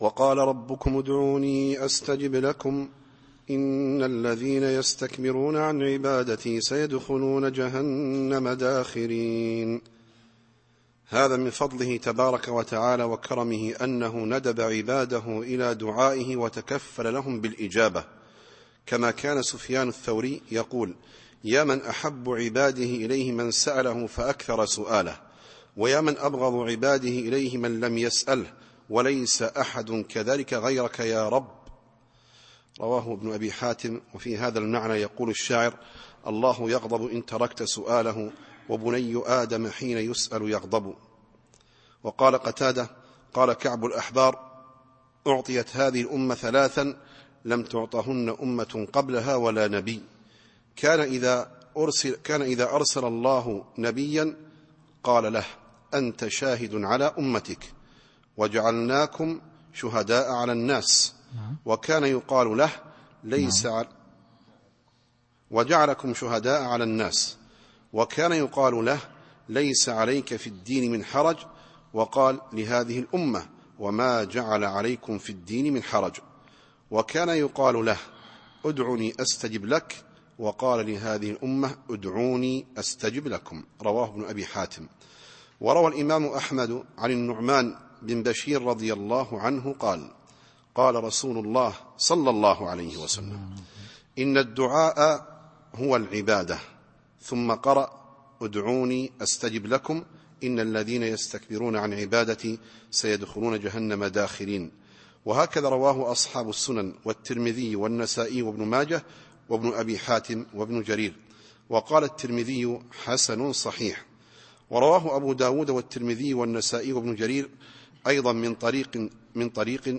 التفسير الصوتي [غافر / 60]